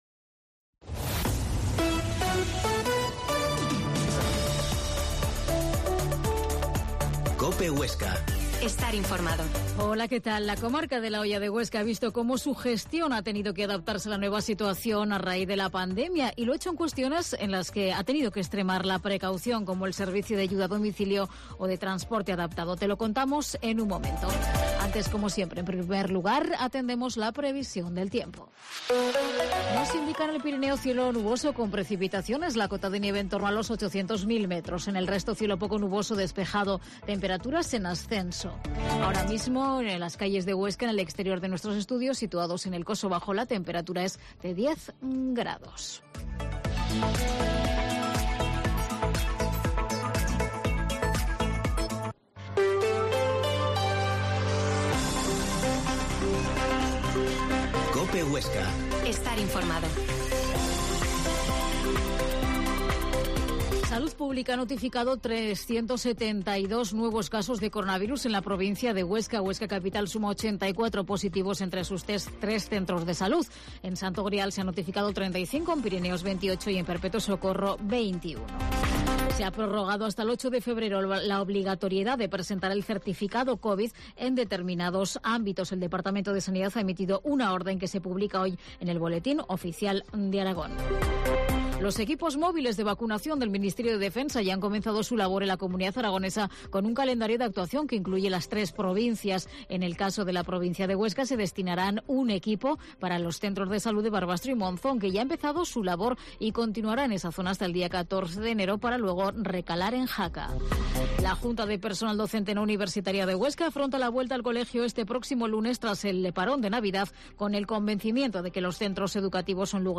Mediodía en Cope Huesca 13,20h. Entrevista al pte de la comarca de la Hoya de Huesca, Jesús Alfaro